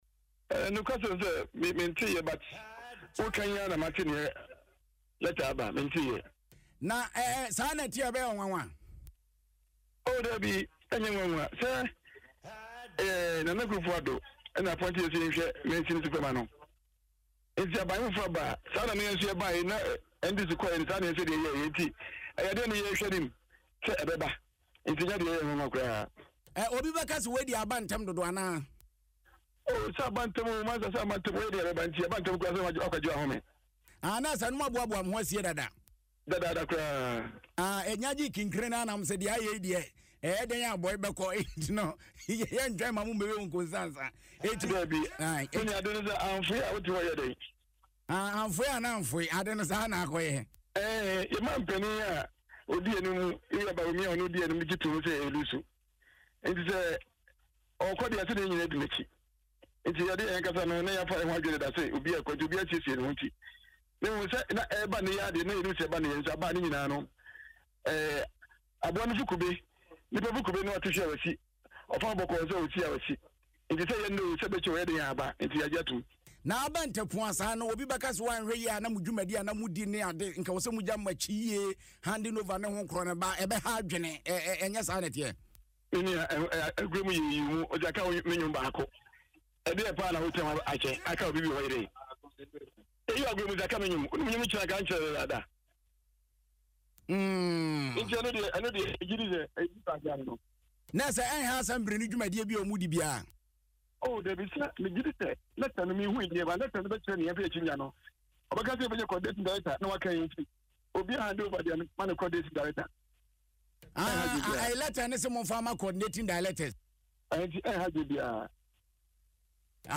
In an interview on Adom FM’s Midday News, Kasiebo is Tasty, Mr. Amponsah acknowledged that the announcement was sudden.